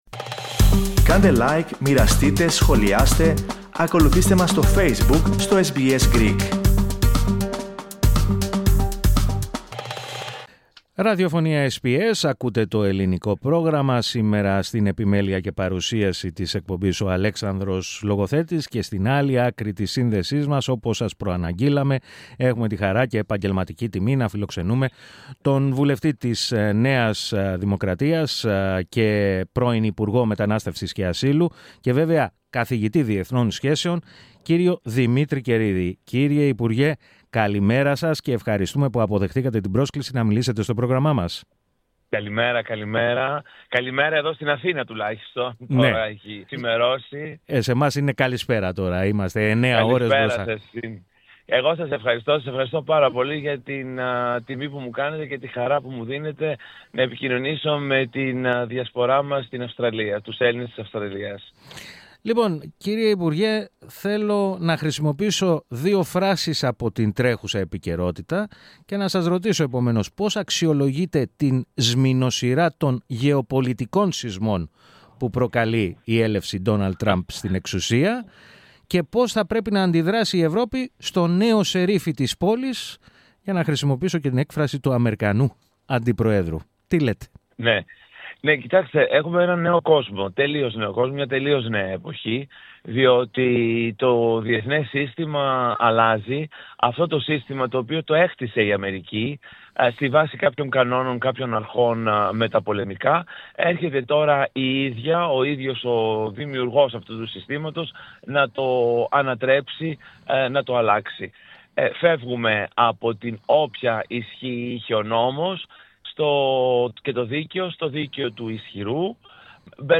Ο βουλευτής της ΝΔ, πρώην υπουργός και καθηγητής Διεθνών Σχέσεων, Δημήτρης Καιρίδης, μίλησε στο Ελληνικό Πρόγραμμα της ραδιοφωνίας SBS, με αφορμή και τις πρόσφατες επαφές που είχε στην Ουάσιγκτον, με κορυφαίους γερουσιαστές και βουλευτές του λεγόμενου Greek Caucus. Δηλαδή, της ομάδας του Αμερικανικού Κογκρέσου που προωθεί και παλεύει για τα ελληνικά εθνικά θέματα.